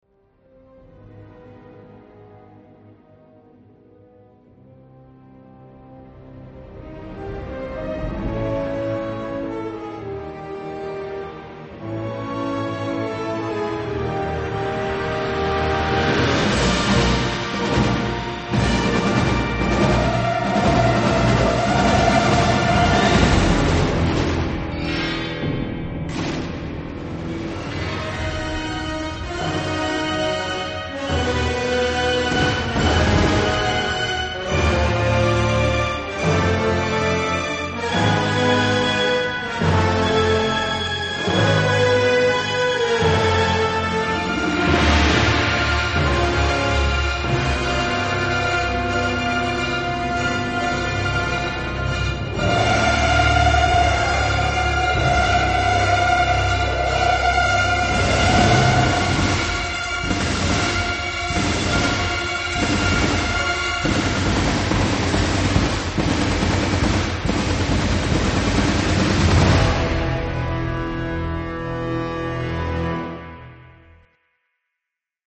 冒頭からいきなり聞くことが出来るニュー・テーマをうるさ過ぎるほどのパーカッションと唸るホルンの高音が書き消します。
ほとんどテーマ性のない音楽。